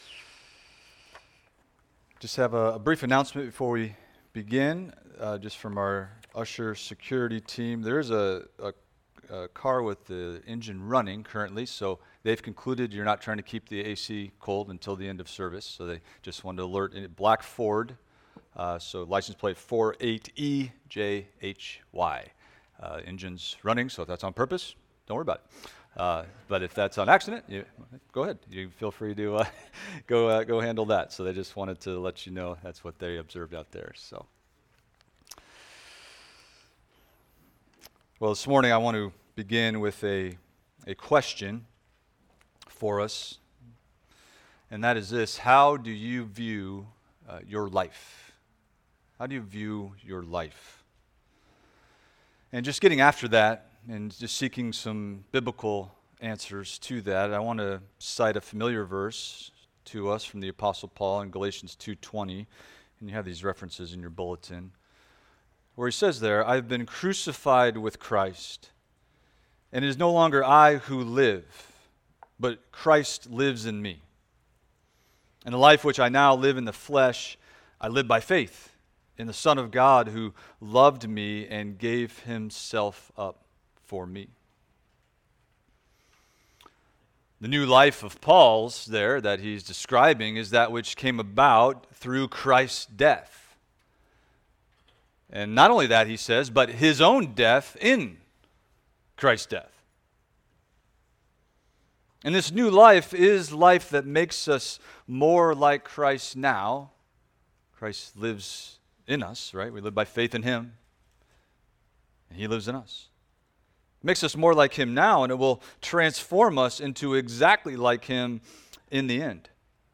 Expository Preaching from the Book of Acts – Acts 20:1-16 – The Exemplary Life of an Apostle